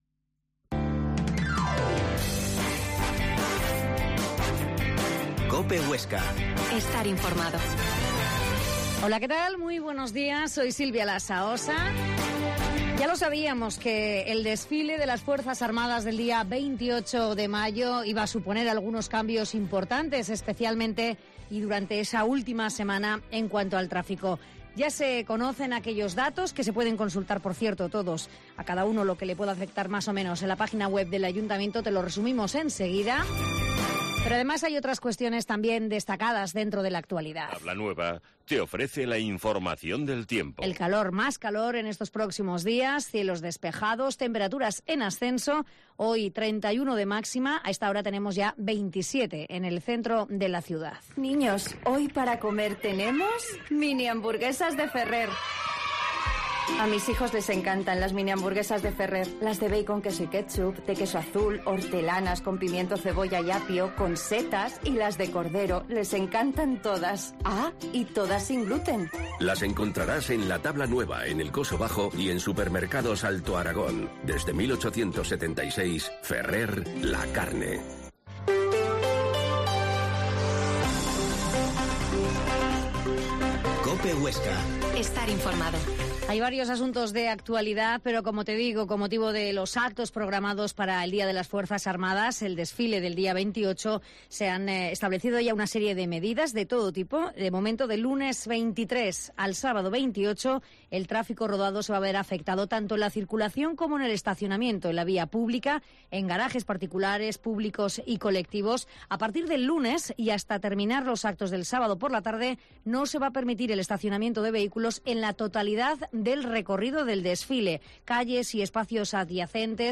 Herrera en COPE Huesca 12.50h Entrevista al concejal de medio ambiente Roberto Cacho